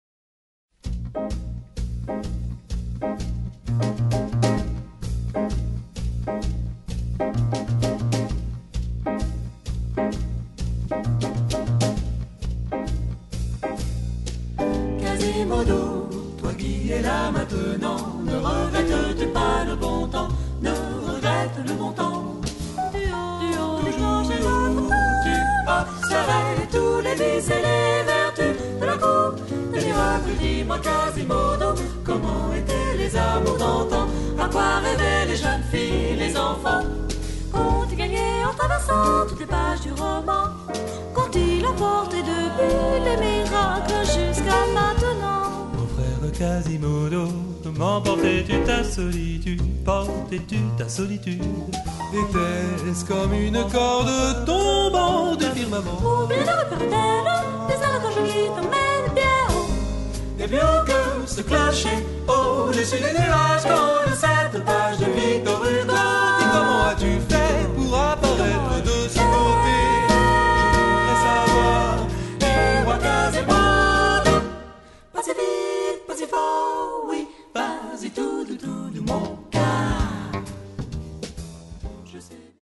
Les chanteurs du premier quatuor étaient :